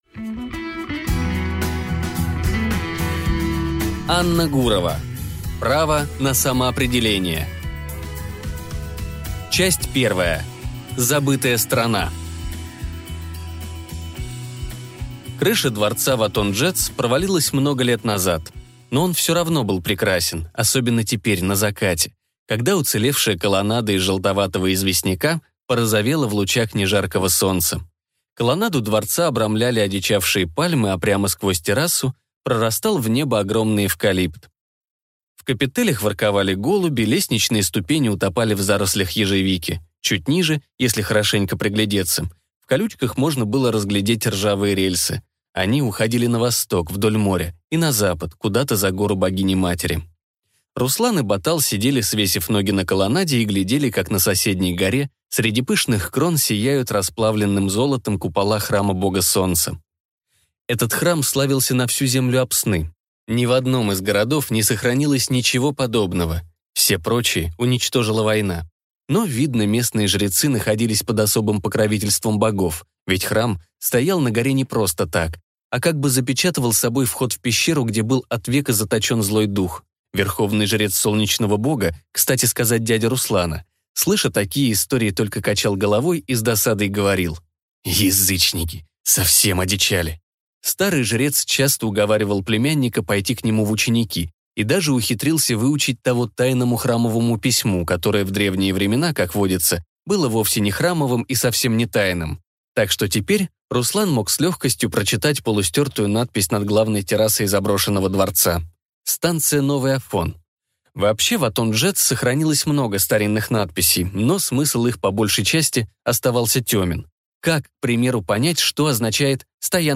Аудиокнига Право на самоопределение | Библиотека аудиокниг